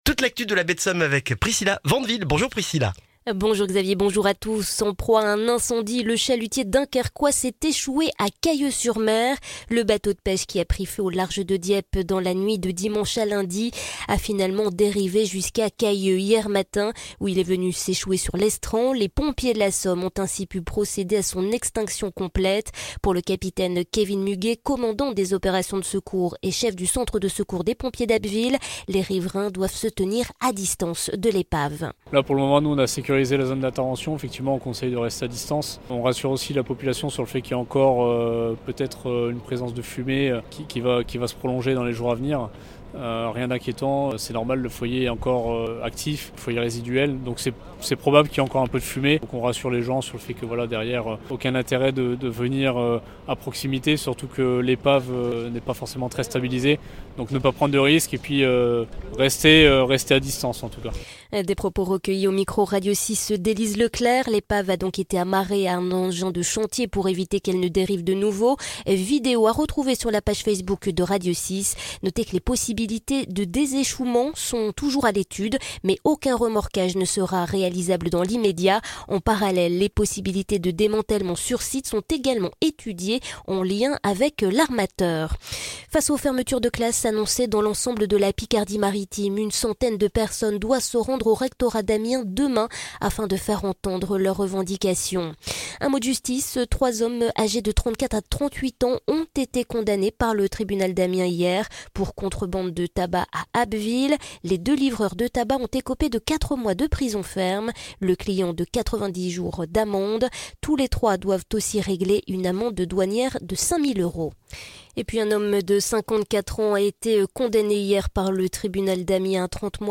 Le journal du mercredi 14 février en Baie de Somme et dans la région d'Abbeville